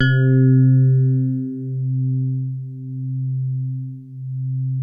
TINE HARD C2.wav